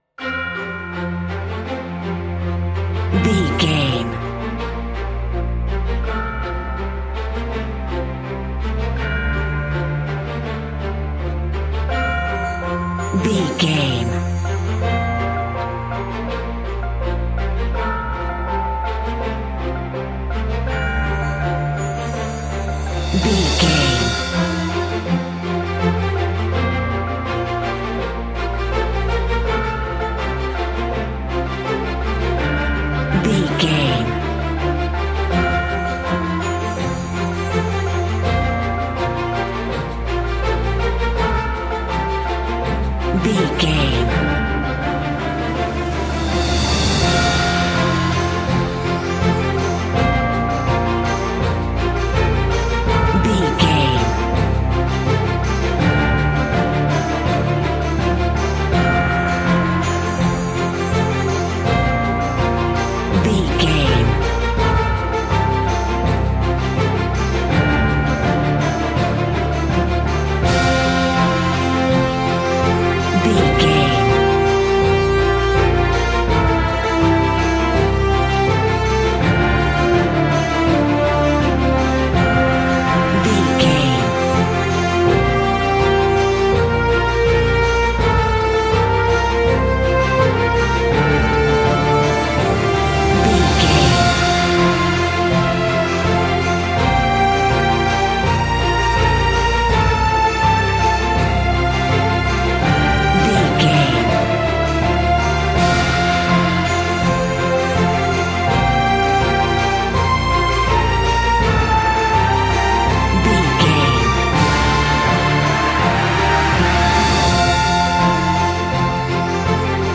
Uplifting
Ionian/Major
epic
brass
cello
flutes
horns
oboe
strings
synthesizer
trumpet
violin